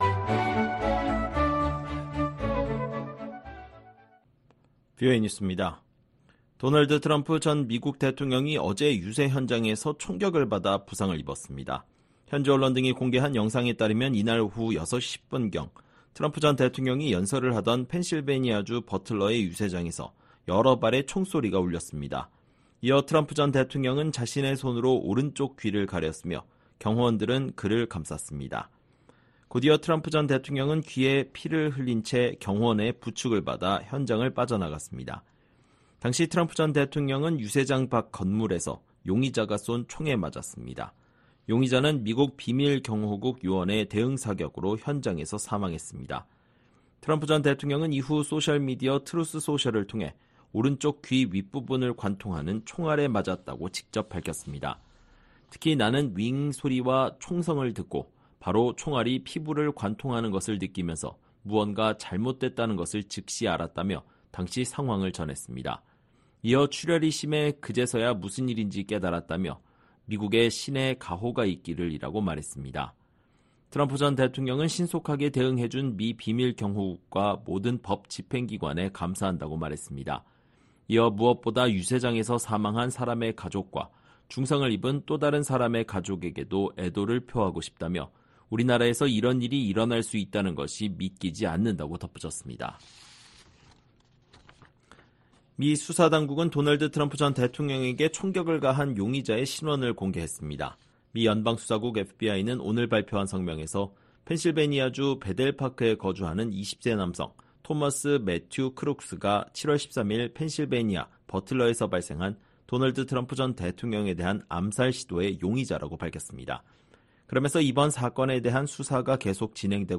VOA 한국어 방송의 일요일 오후 프로그램 2부입니다. 한반도 시간 오후 9:00 부터 10:00 까지 방송됩니다.